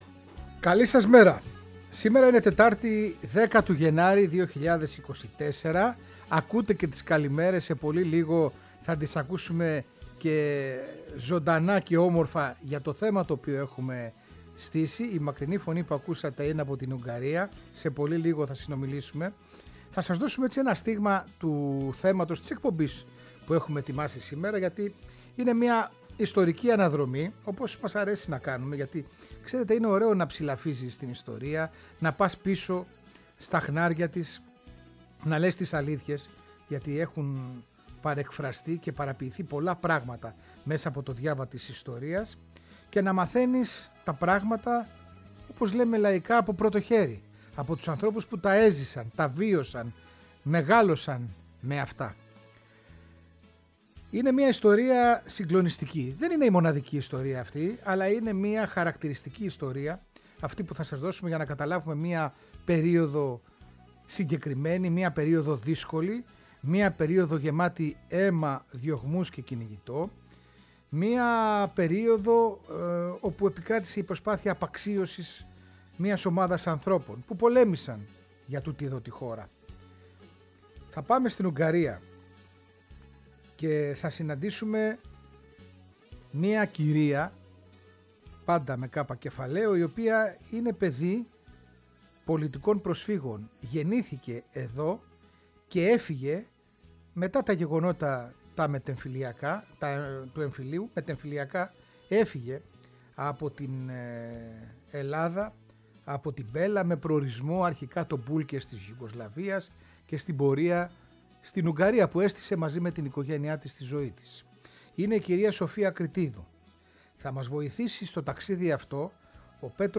Μας περιγράφει με ασυγκράτητη συγκίνηση… εκείνο το πρώτο αντάμωμα με τη μητέρα της μετά από χρόνια…
Συνεντεύξεις